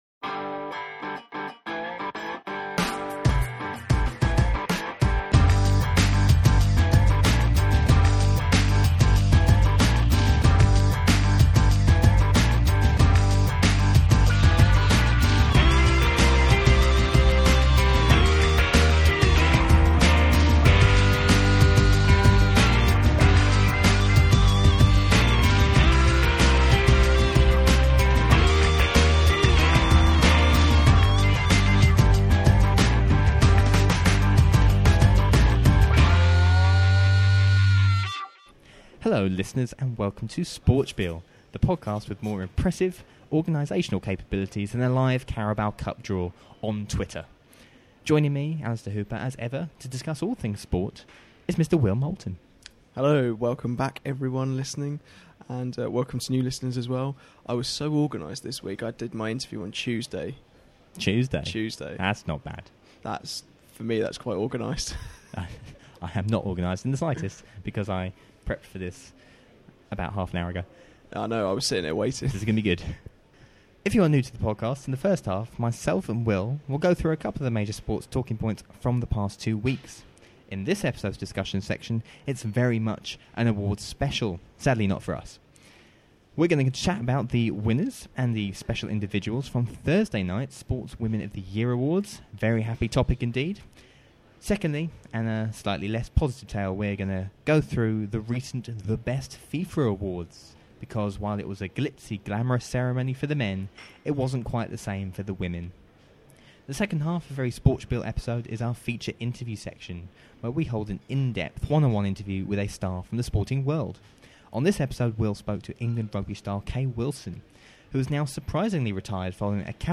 Our feature interview segment in this episode is with England Rugby star Kay Wilson (25:11). After announcing her surprise retirement from international rugby earlier in October, the wing tells us why she made her decision. She also looks back on the highlights of her stellar career and takes us through the heartbreak of losing the 2017 World Cup final to New Zealand.